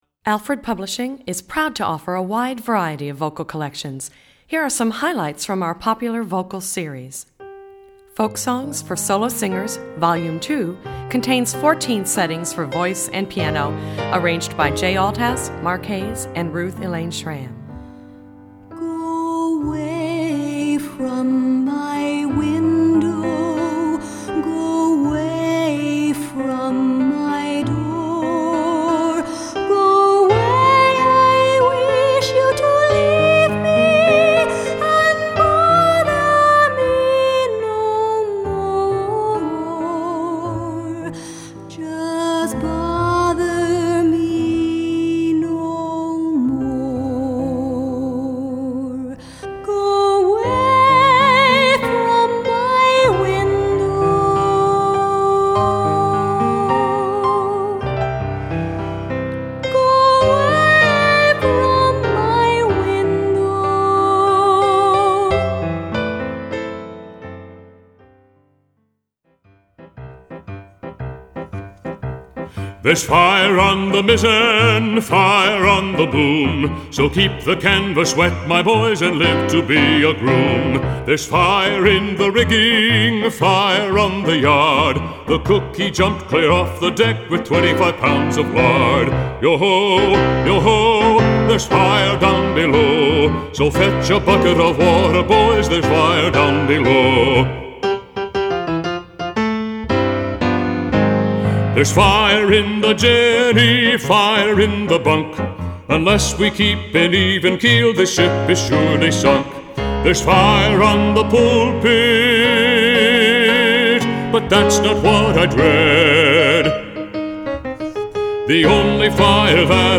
Voicing: Medium-Low Voice and Audio Access